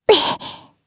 Lewsia_A-Vox_Casting2_jp.wav